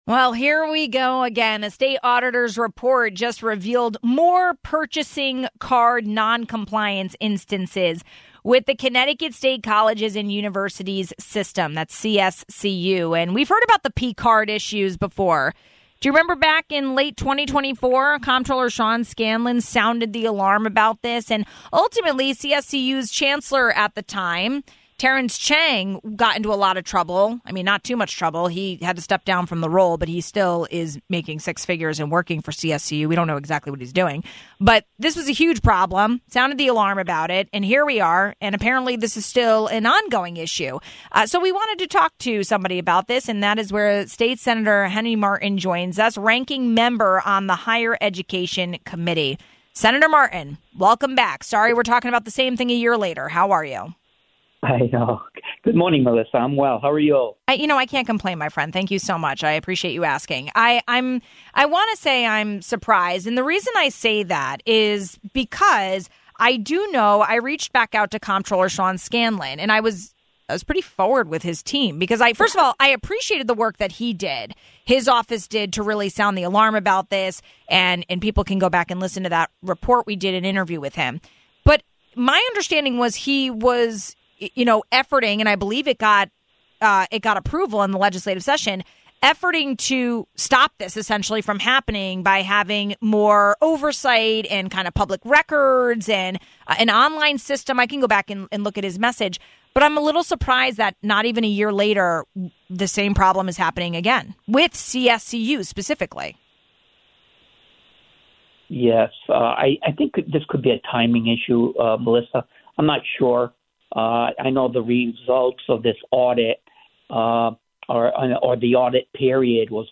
We asked Ranking Member on the Higher Education Committee, Senator Henri Martin.